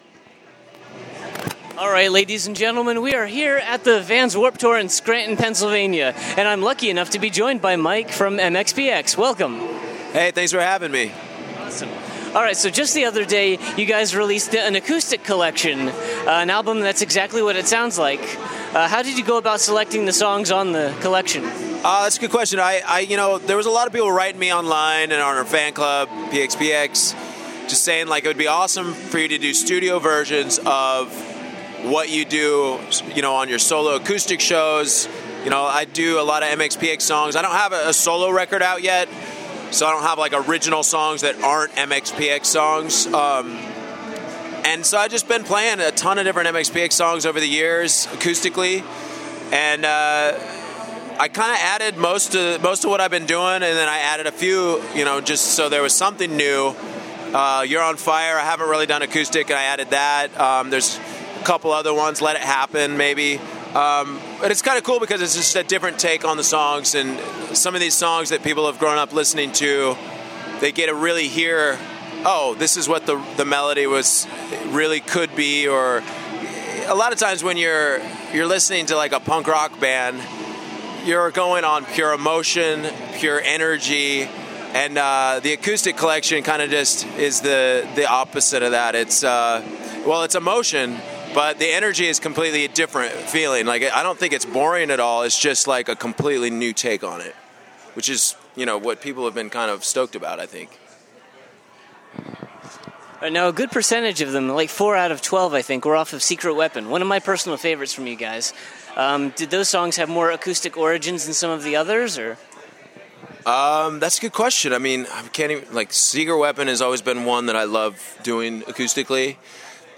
Exclusive: Mike Herrera Interview
Cut to about 2 ½ years later at Warped Tour.
48-interview-mike-herrera.mp3